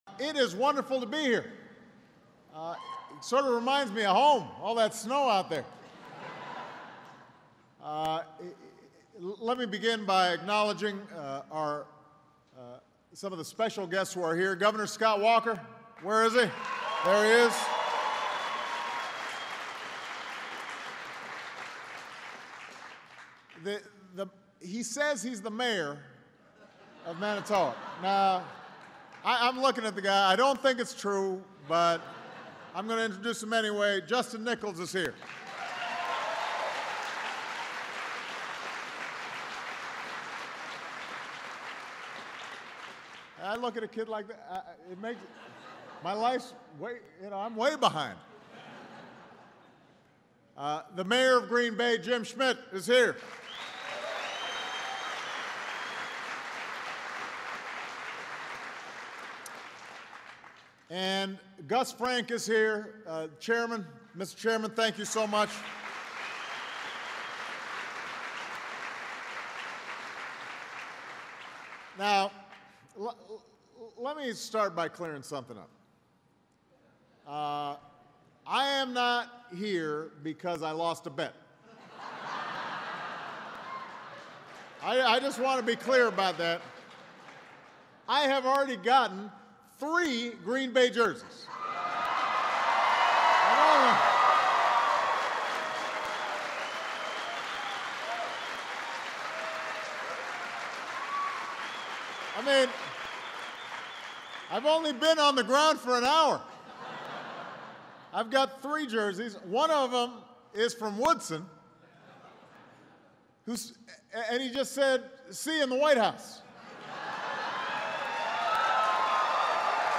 U.S. President Barack Obama speaks at the Orion Energy Systems Corporation in Manitowoc, WI